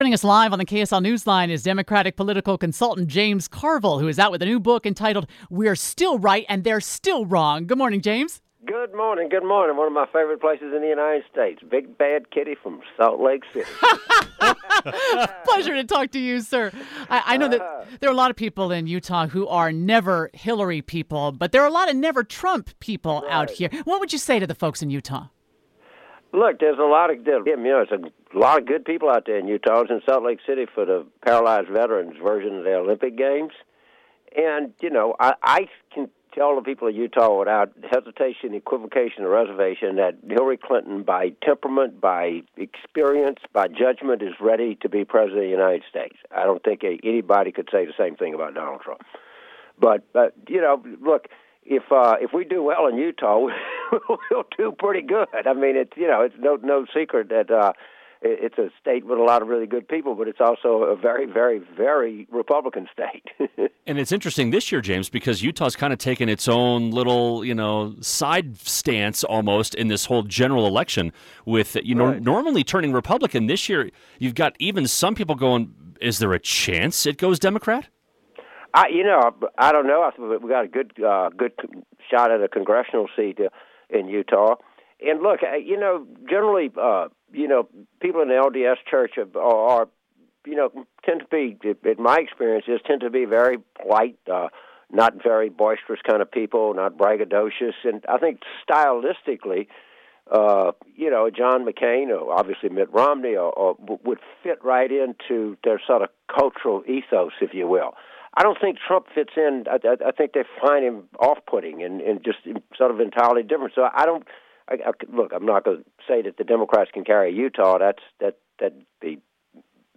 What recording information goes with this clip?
spoke live